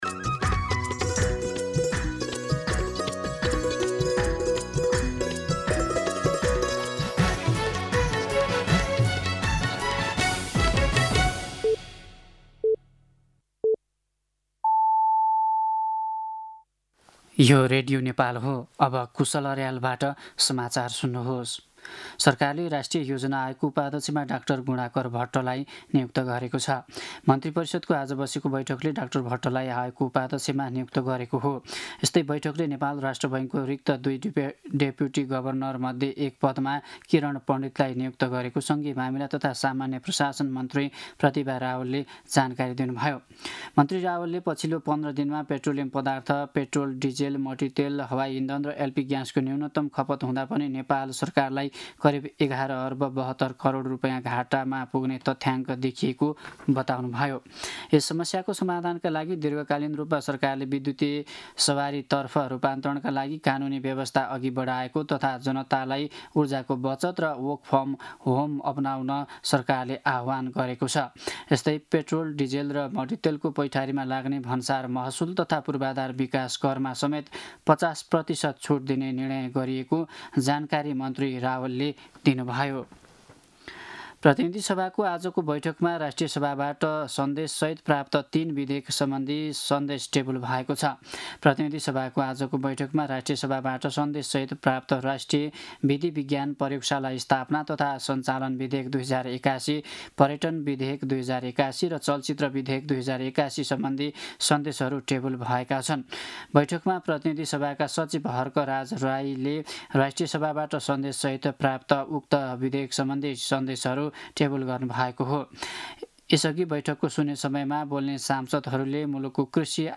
दिउँसो ४ बजेको नेपाली समाचार : २४ चैत , २०८२